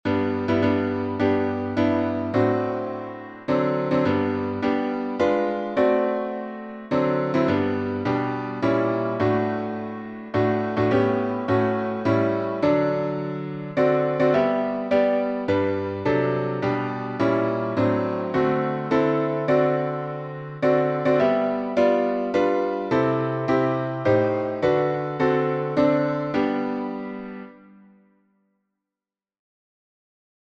There's a Song in the Air — alternate harmonies.